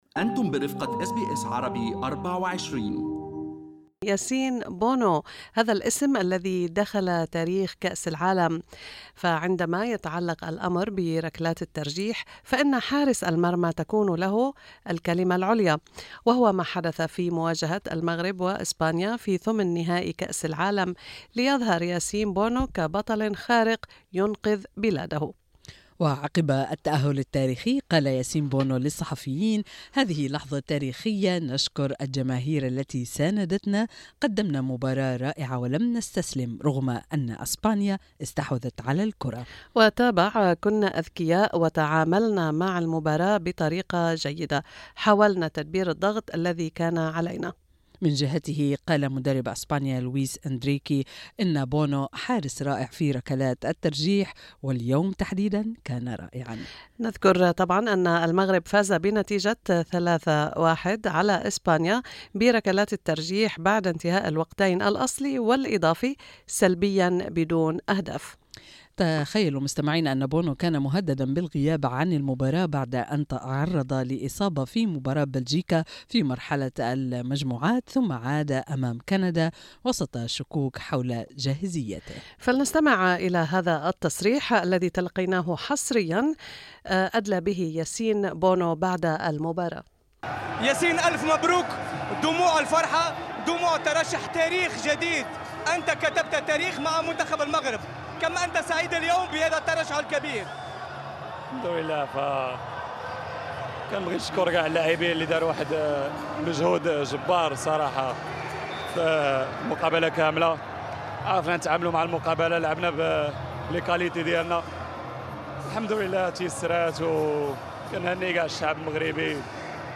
"رجل المباراة": لقاء حصري مع حارس المرمى المغربي ياسين بونو بعد الفوز التاريخي لأسود الأطلس في كأس العالم